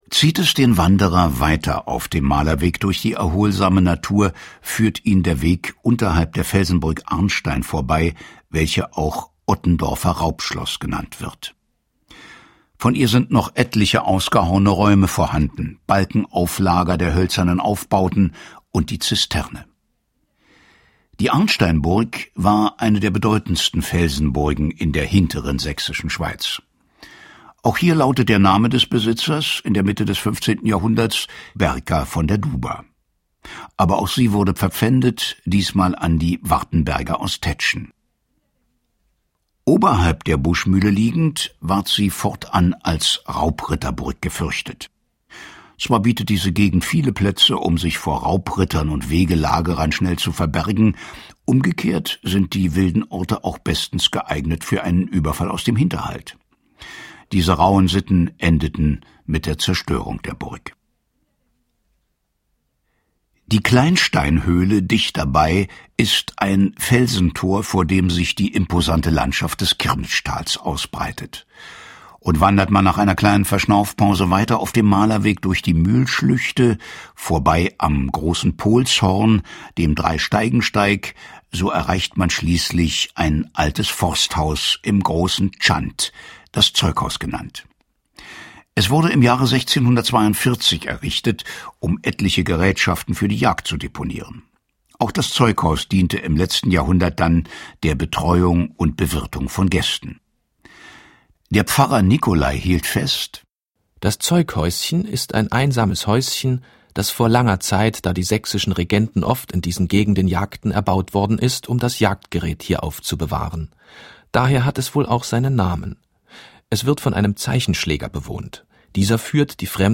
Gunther Schoß ist als "Berliner mit der markanten Stimmme" einer der gefragtesten deutschen Schauspieler.